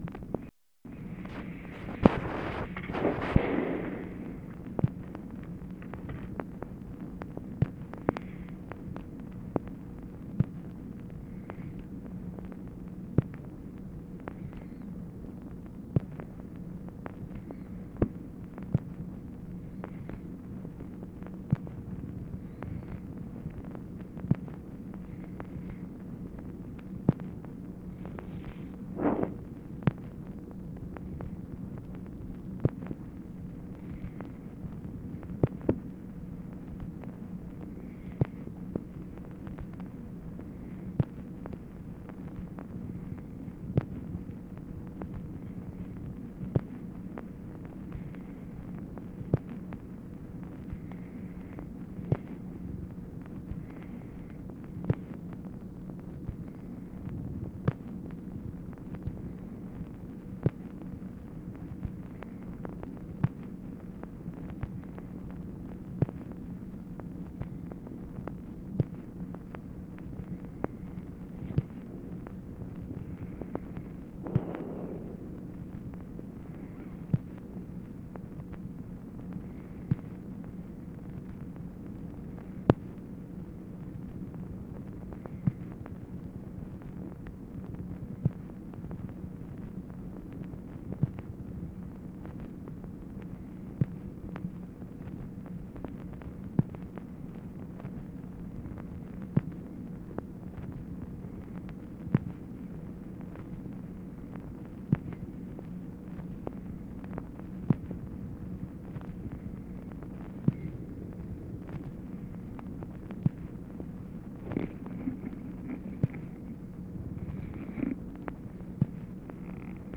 LBJ ON HOLD WHILE TELEPHONE OPERATOR TRIES TO LOCATE AN UNIDENTIFIED MALE; OPERATOR TELLS LBJ THAT HE WILL BE CALLED BACK LATER
Conversation with TELEPHONE OPERATOR, February 7, 1964
Secret White House Tapes